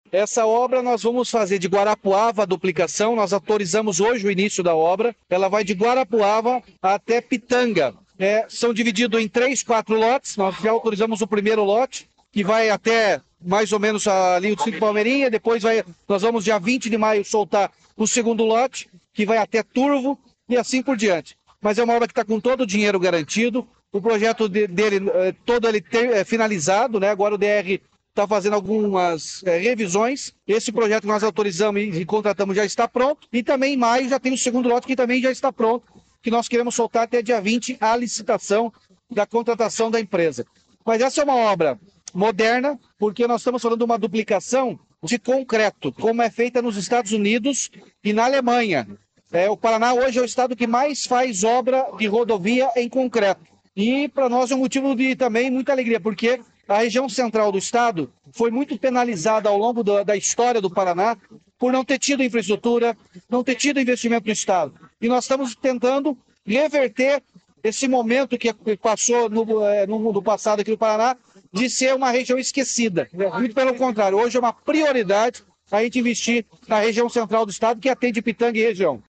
Sonora do governador Ratinho Junior sobre o anúncio da licitação que dará início à duplicação da PRC-466, em Guarapuava